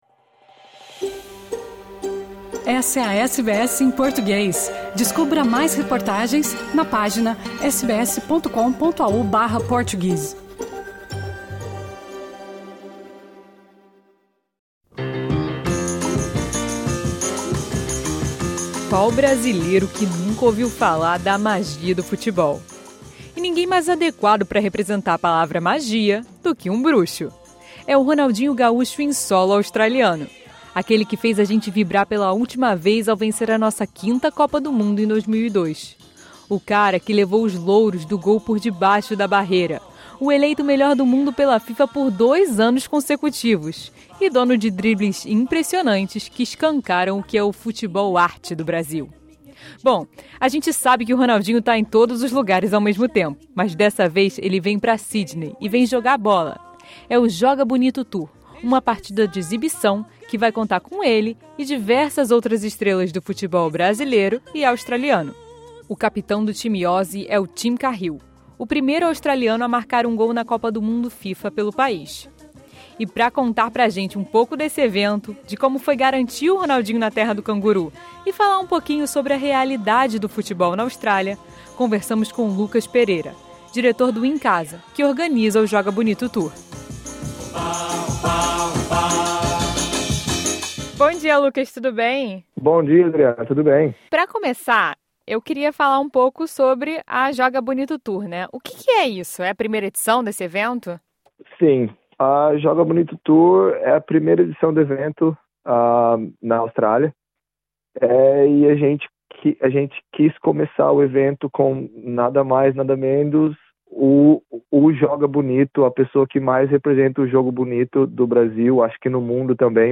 Ronaldinho Gaúcho chega na terra dos cangurus em novembro, para um amistoso entre ex-jogadores brasileiros e ex-jogadores australianos, estes últimos liderados por Tim Cahill. Conversamos com